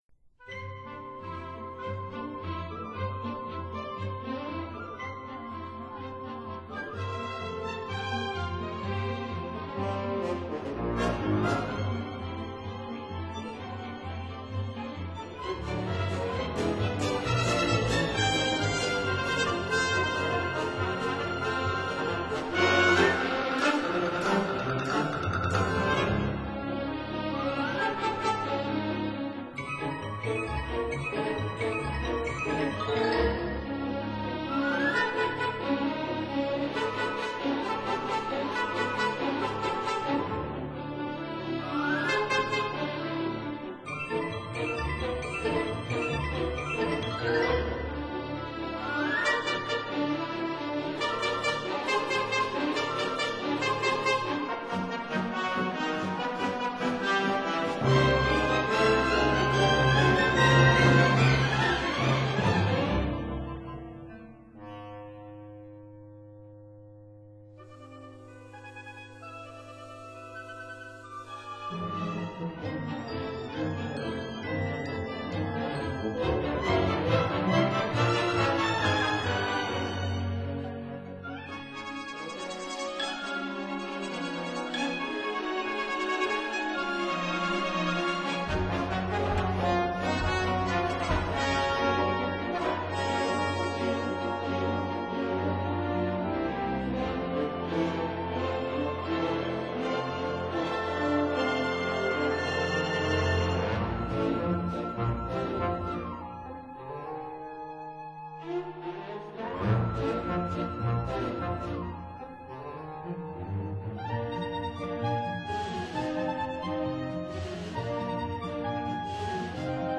original jazz band version